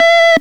Le fonctionnement est bizarre, car les sons qui sortent sont différents après chaque utilisation - mais pas tout le temps.
Vous aussi vous voulez utiliser le Synthétiseur Aléatoire du Microcosme ?